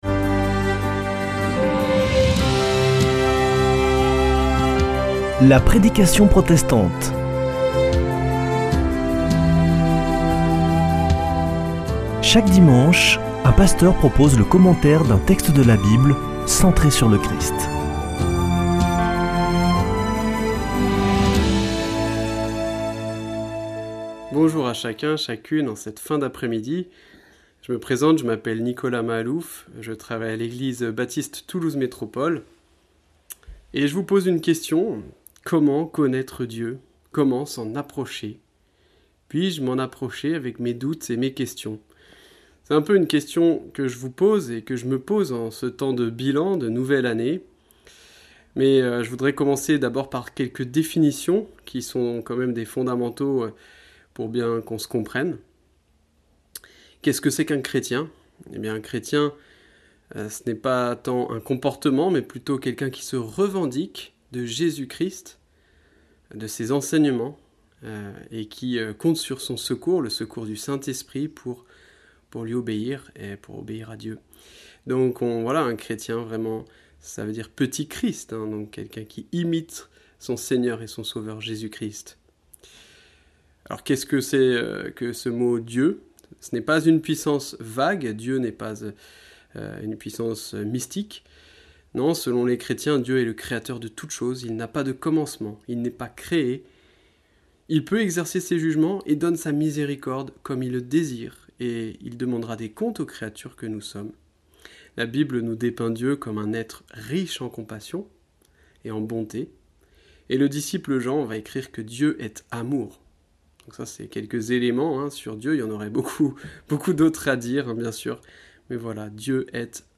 Accueil \ Emissions \ Foi \ Formation \ La prédication protestante \ Un nouveau départ dans la foi ?
Une émission présentée par Des protestants de la région